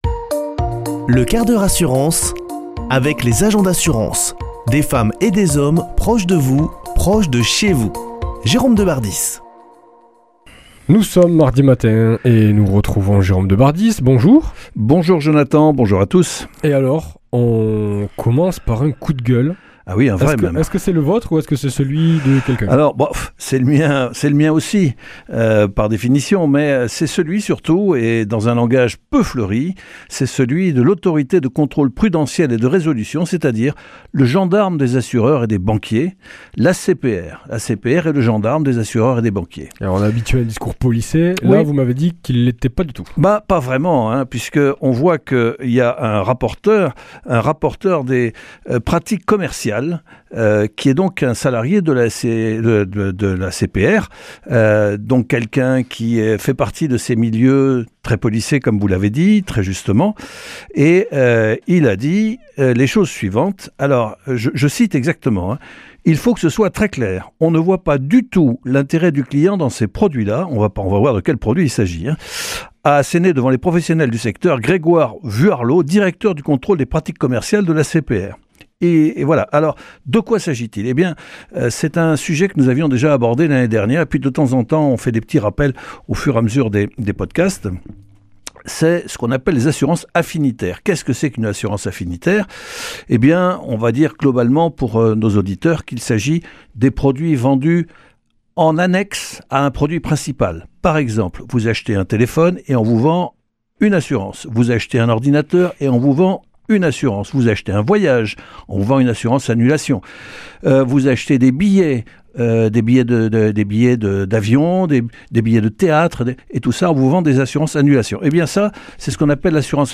mardi 2 avril 2024 Chronique le 1/4 h assurance Durée 5 min
Une émission présentée par